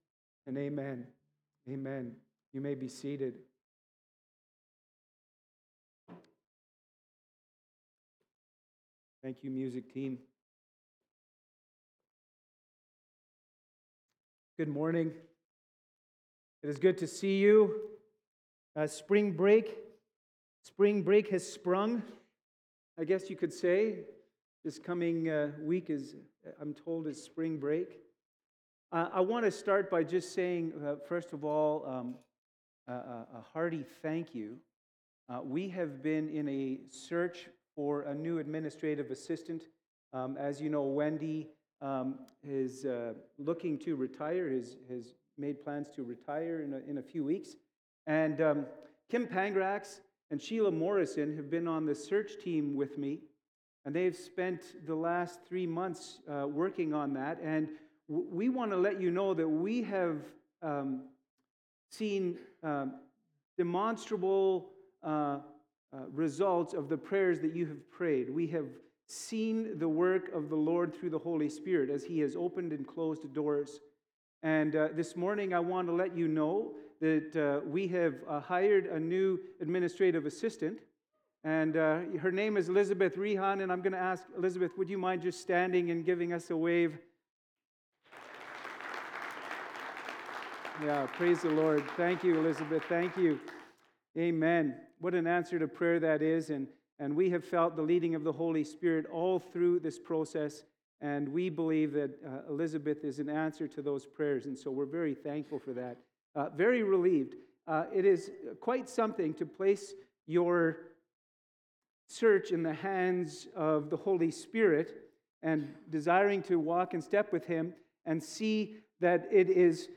Sermons | Westview Baptist Church